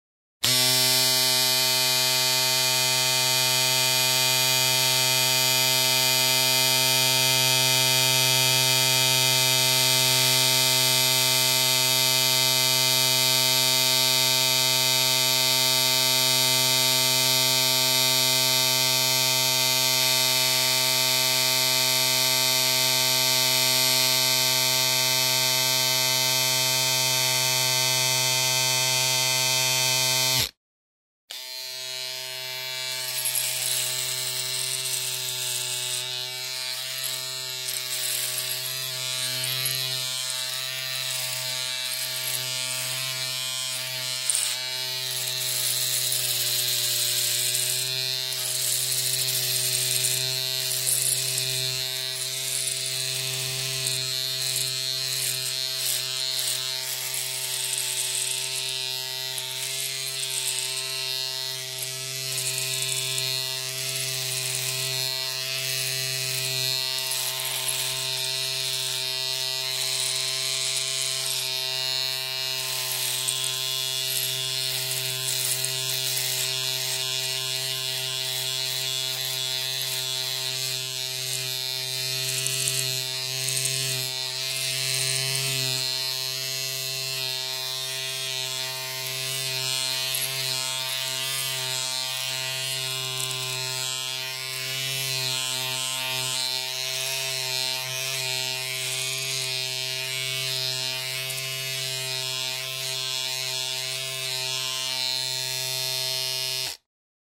Электрическая бритва для гладкого бритья